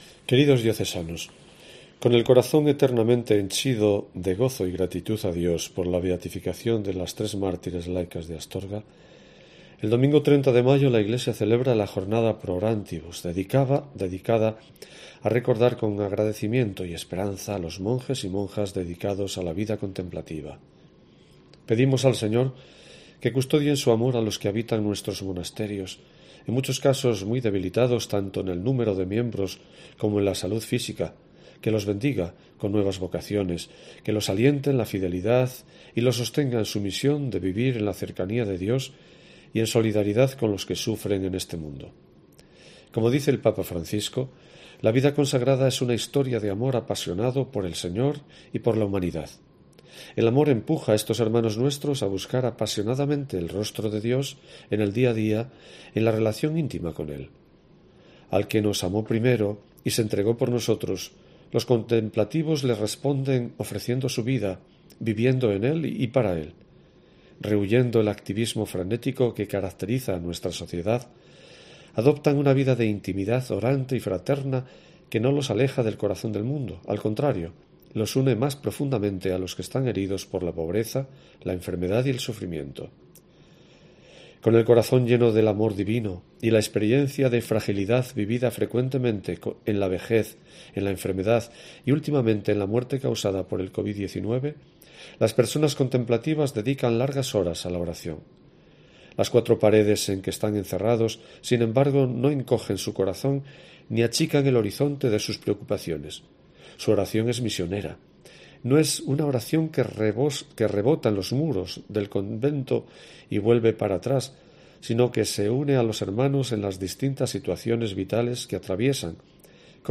Escucha aquí la carta de esta semana del obispo de Astorga